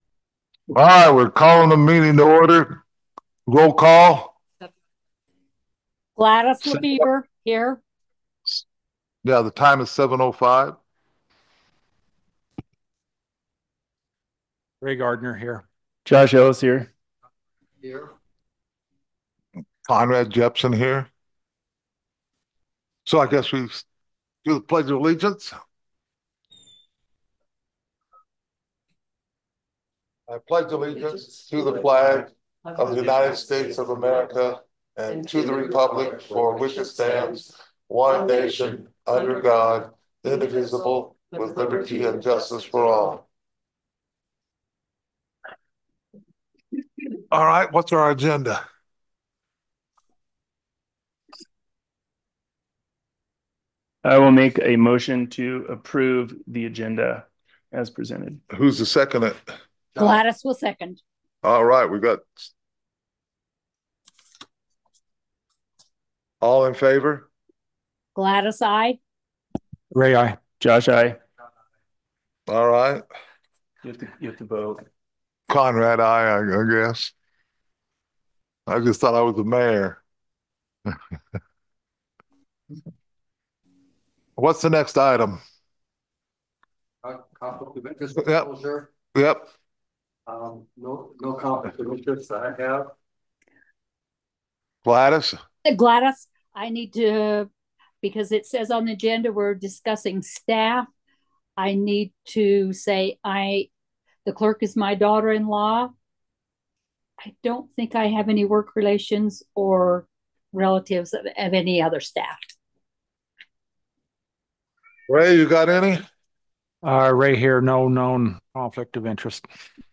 The Boulder Town Council will hold a special meeting on Wednesday, April 23, 2025, starting at 7:00 pm at the Boulder Community Center Meeting Room, 351 North 100 East, Boulder, UT. Zoom connection will also be available.